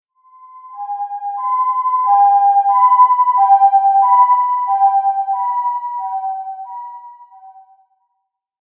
救急車通過走行音なし.mp3